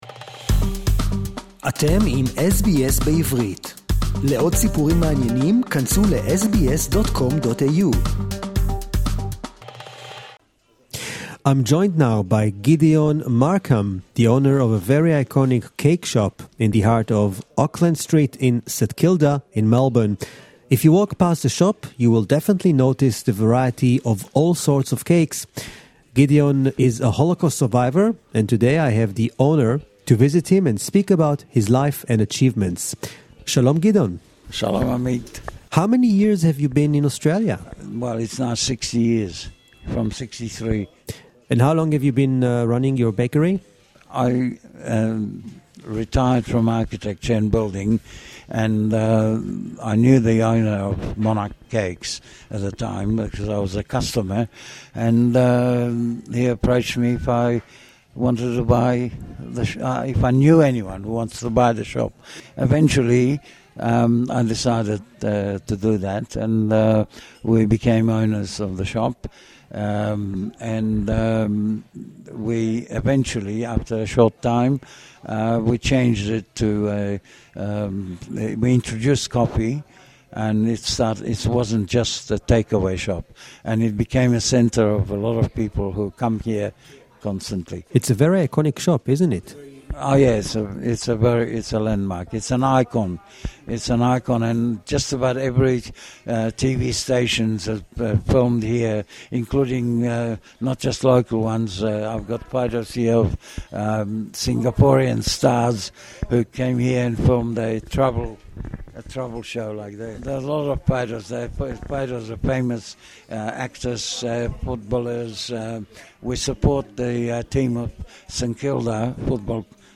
The King of Monarch (English interview)